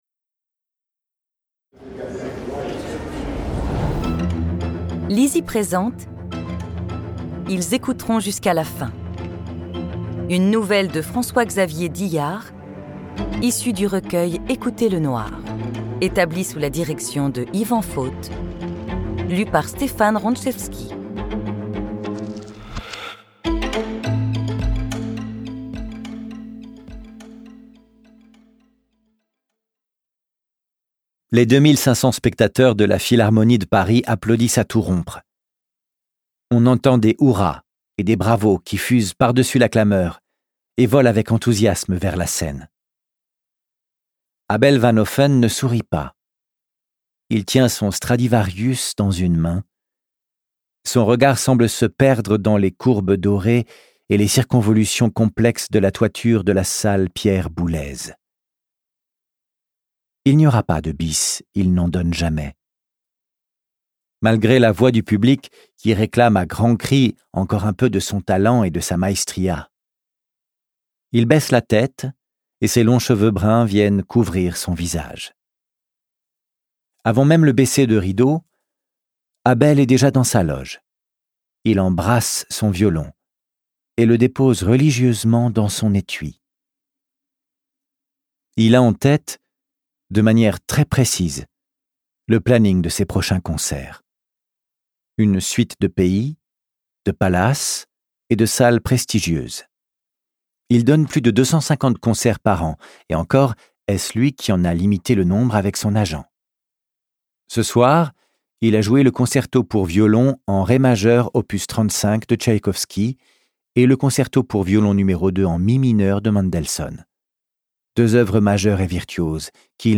Click for an excerpt - Ils écouteront jusqu'à la fin de François-Xavier DILLARD